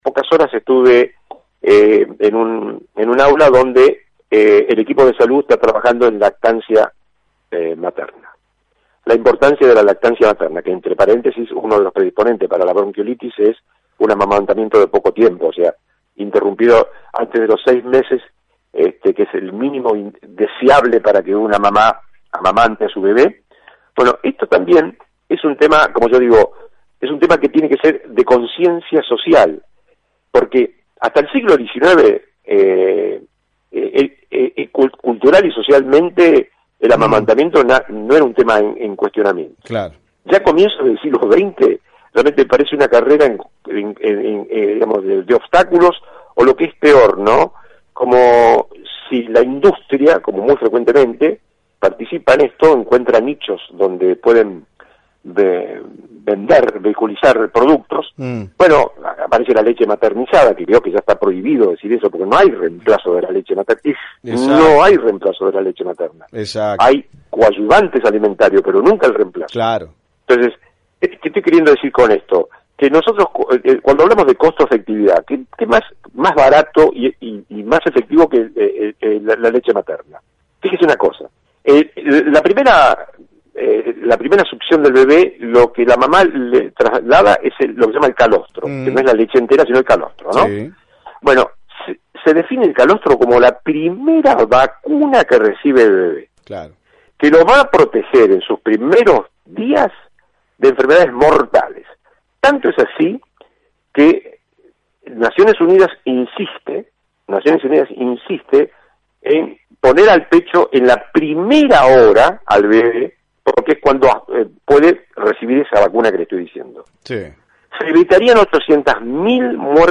Destacó el refuerzo en la atención a la comunidad y el esfuerzo de poder llevar un médico a cada pueblo. También se refirió a temas vertebrales como la lucha contra la bronquiolitis, aborto y lactancia materna. Escuchá la entrevista completa aquí.